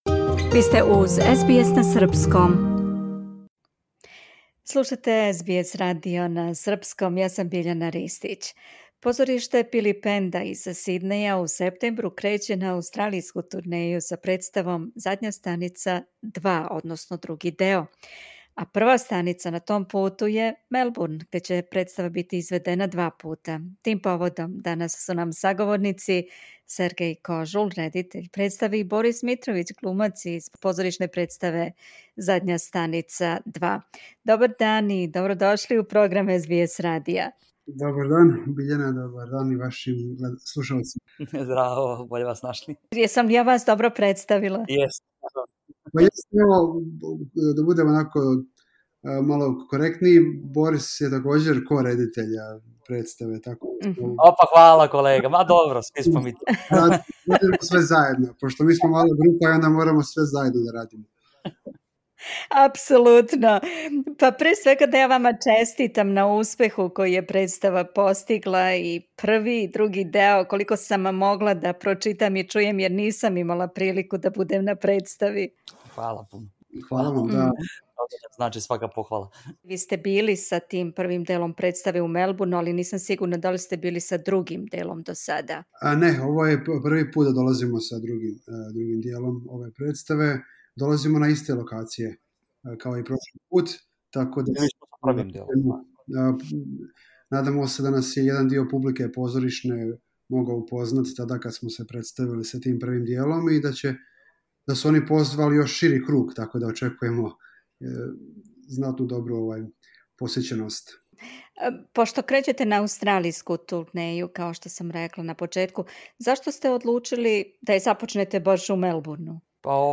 Позориште Пилипенда из Сиднеја креће на аустралијску турнеју са представом "Задња станица 2", a прва станица на том путу је Мелбурн, где ће представа бити изведена два пута. Тим поводом су нам саговорници глумци